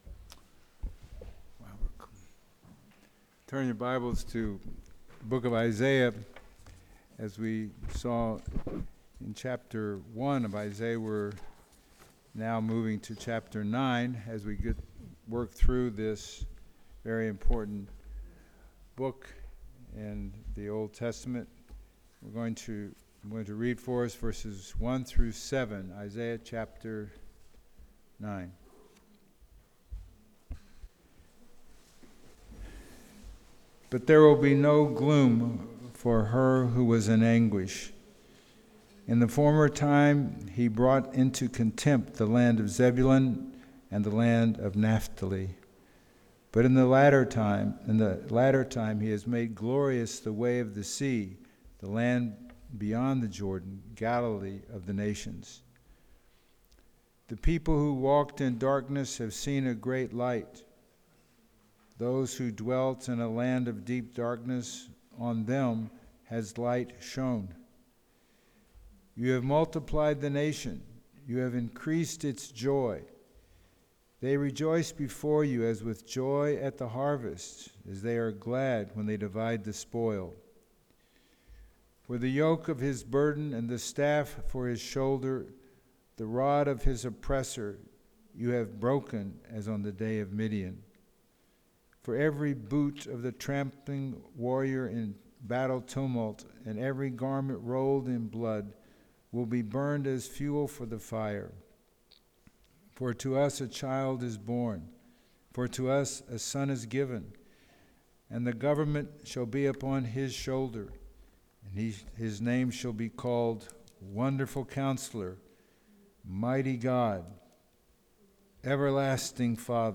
Sermons | The Bronx Household of Faith
Service Type: Sunday Morning